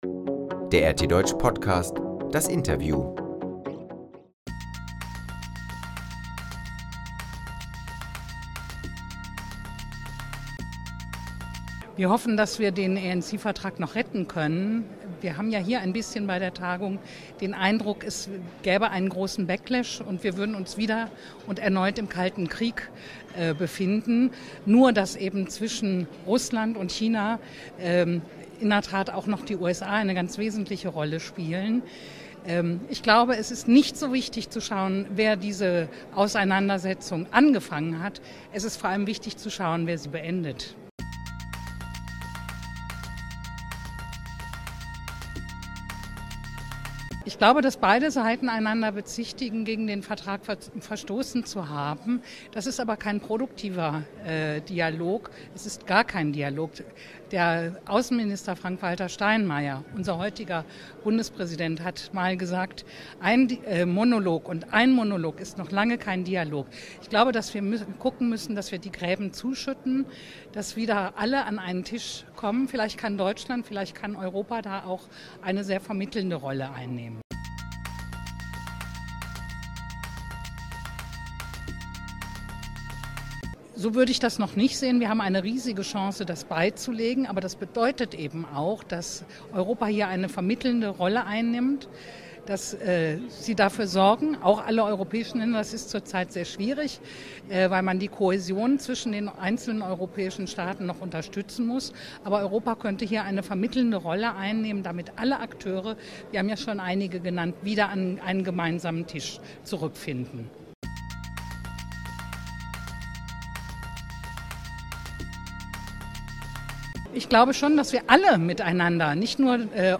RT Deutsch hat die Möglichkeit genutzt und sprach mit der SPD-Bundestagsabgeordneten Daniela De Ridder über die Themen Abrüstung und mögliche Gefahren.